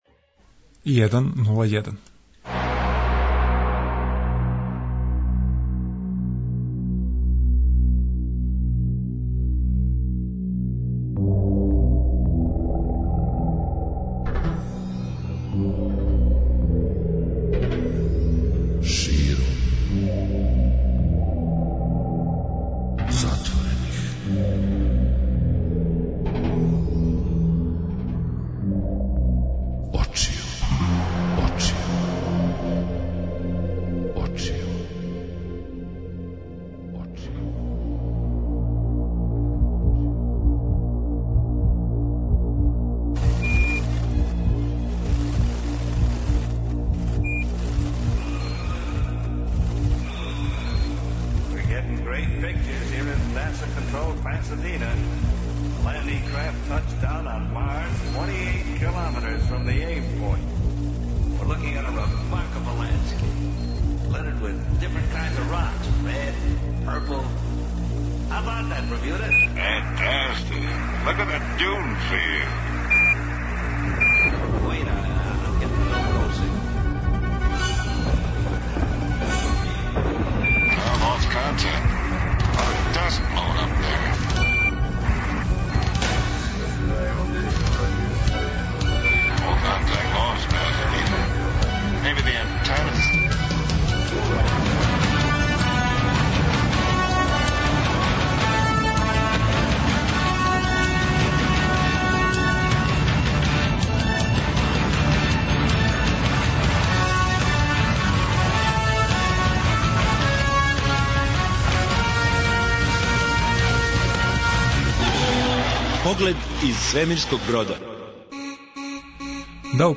Сви они који би да се опробају у писању нека обрате пажњу на снимак са трибине где су представљени резултати конкурса за "Аномалију" јер ће чути "како жири размишља", па ако примене неки од савета које чују, највероватније ће далеко боље проћи у покушају да са неком својом жанровском причом обезбеде себи место у некој будућој збирци.